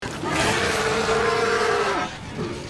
Крик урук-хая (из фильма Властелин Колец)
Боевой клич качайте здесь.